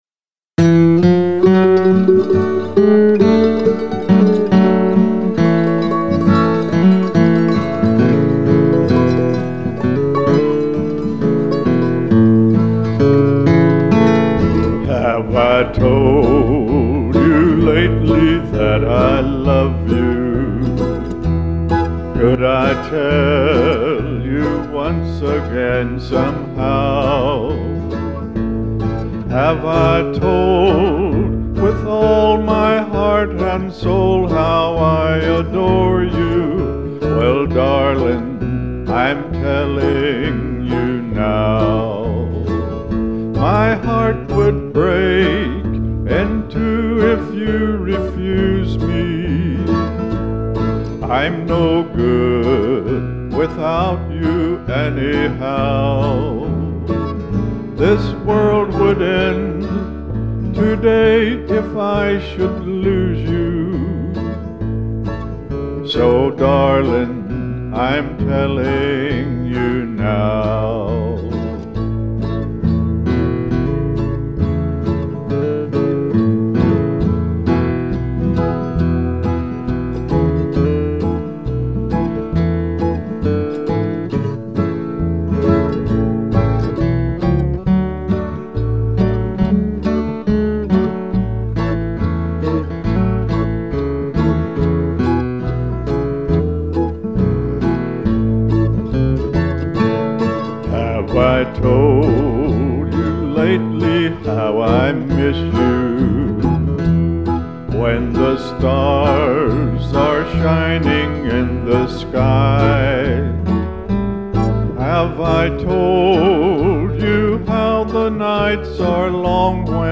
vocals on all songs, except:
all instruments